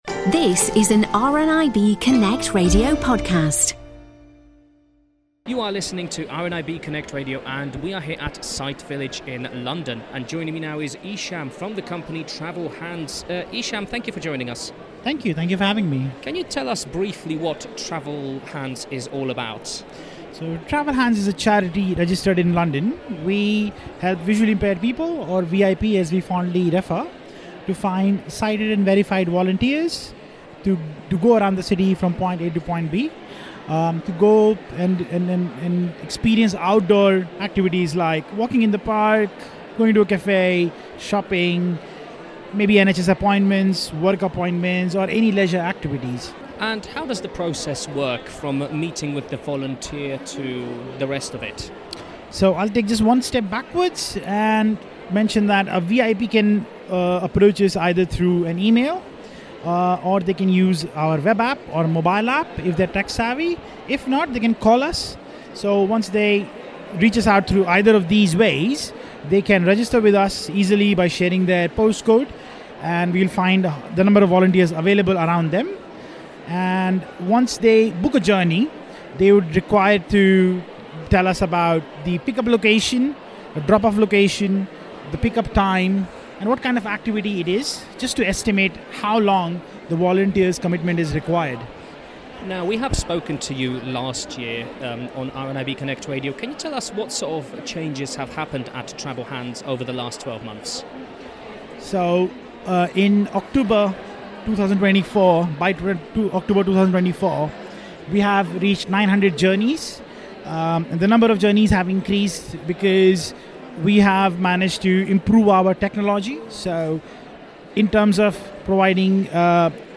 He spoke to one organisation helping to take out one stress out of people London travels.